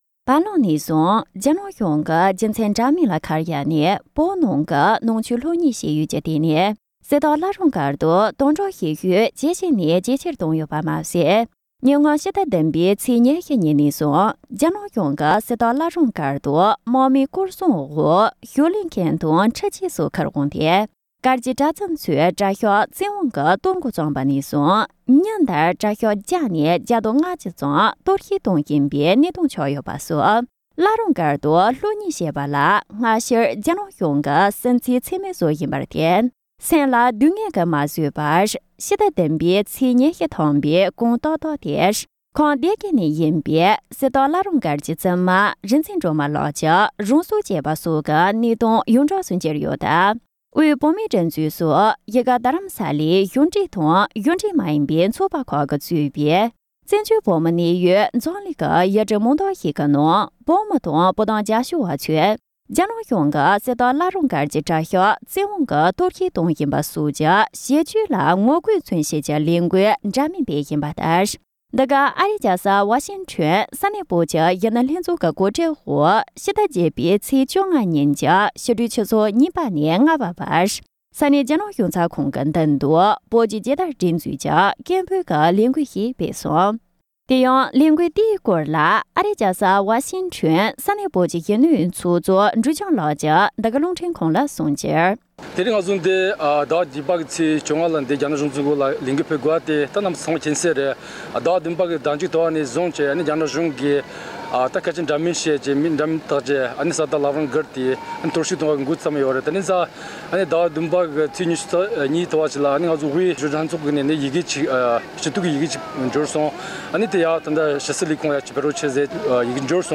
འབྲེལ་ཡོད་མི་སྣ་ཁག་ཅིག་ལ་བཅར་འདྲི་ཞུས་ནས་ཕྱོགས་བསྡུས་བྱས་པ་གསན་རོགས།